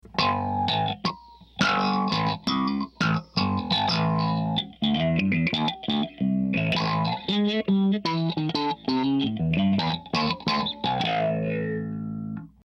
Twin tuned filters sweep your signal creating a unique vocal tone. Two narrow filters sweep your tones under control of the envelope, generating very unique vocal-like sounds.
Clean
BassballsTwin-Dynamic-Envelope-Filter-Clean.mp3